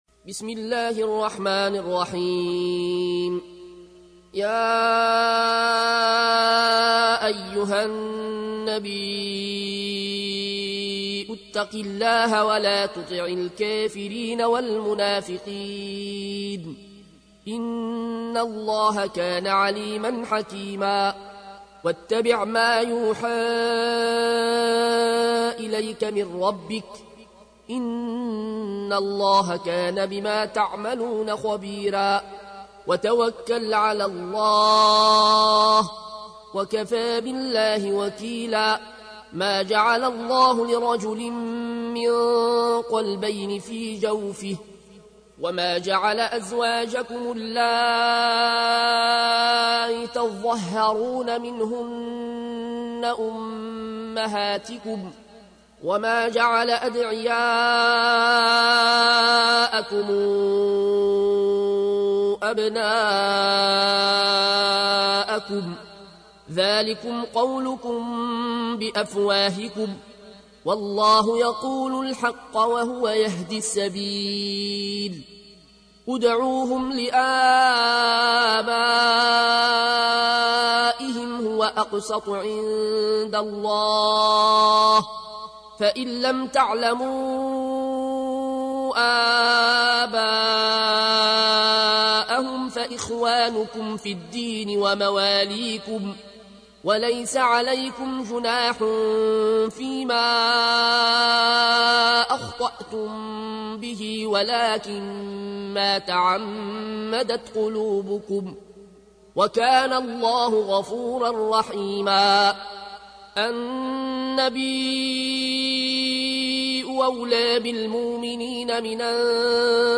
تحميل : 33. سورة الأحزاب / القارئ العيون الكوشي / القرآن الكريم / موقع يا حسين